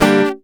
OVATION G-.2.wav